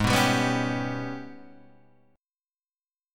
G# Minor Major 11th